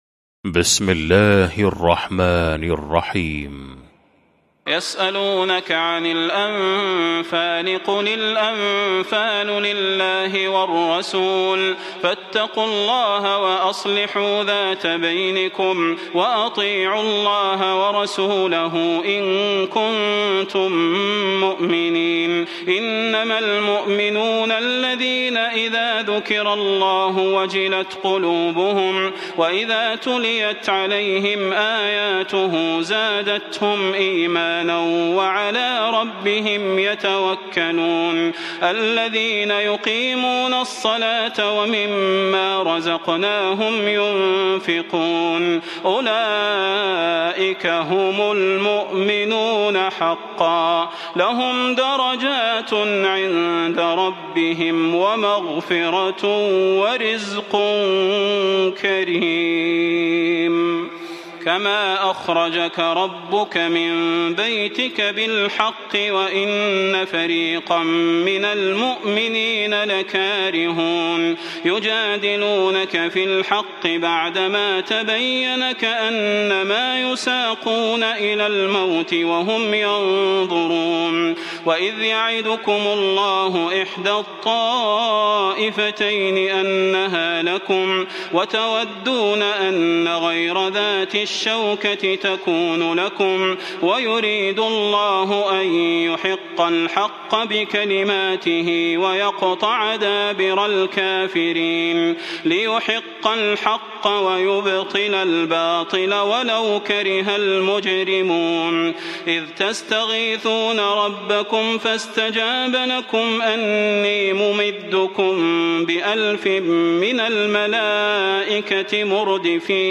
المكان: المسجد النبوي الشيخ: فضيلة الشيخ د. صلاح بن محمد البدير فضيلة الشيخ د. صلاح بن محمد البدير الأنفال